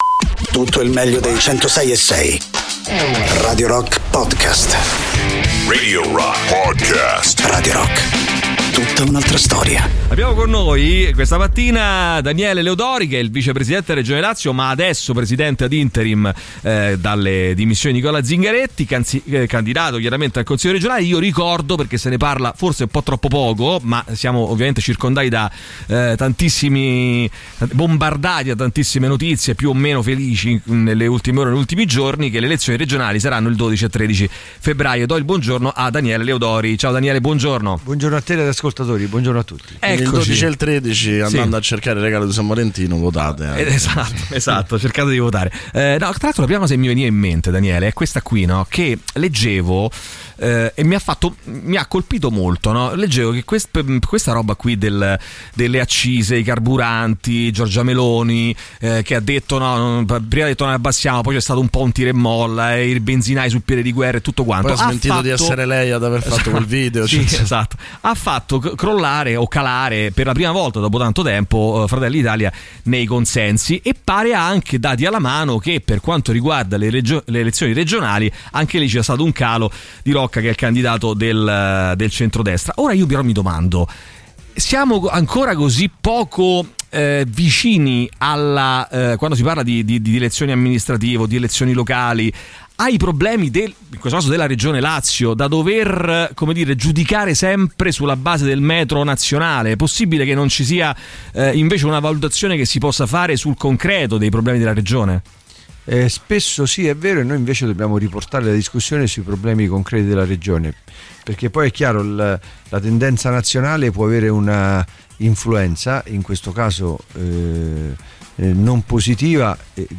Interviste: Daniele Leodori (17-01-23)